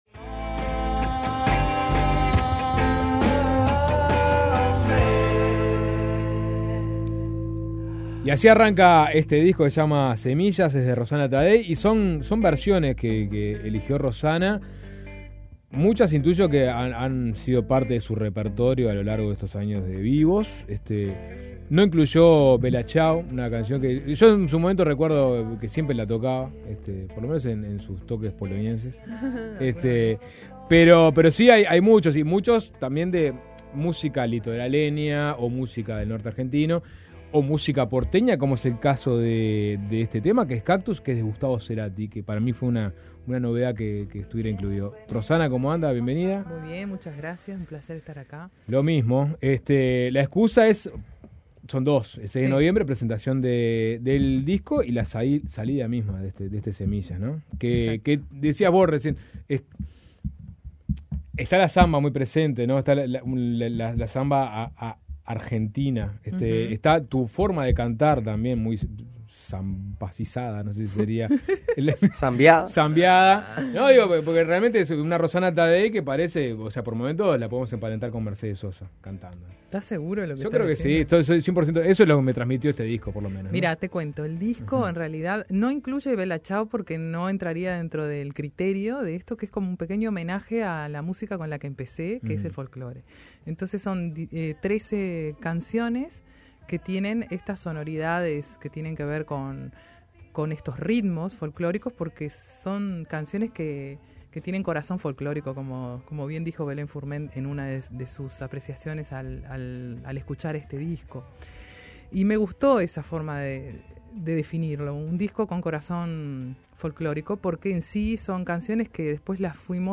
Estuvimos hablando sobre cómo seleccionó las canciones y cantando un poco en la tarde de Suena Tremendo.